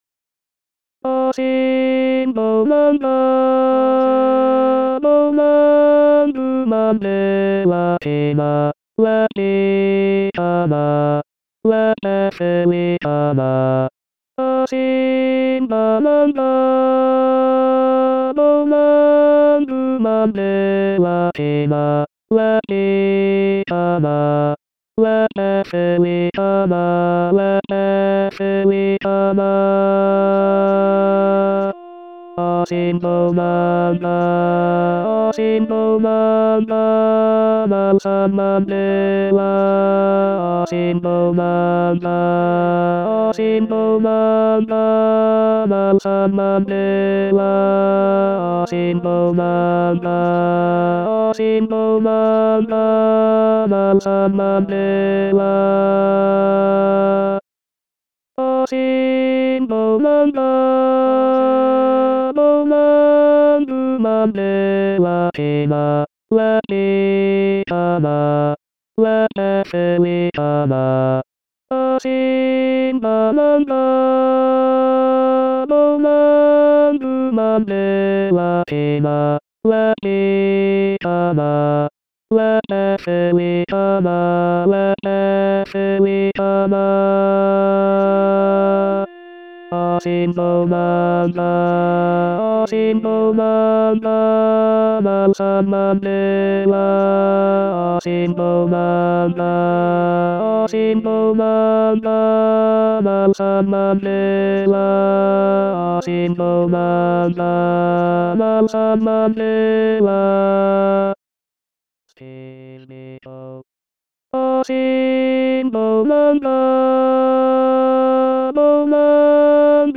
Asimbonanga tEnors 2.mp3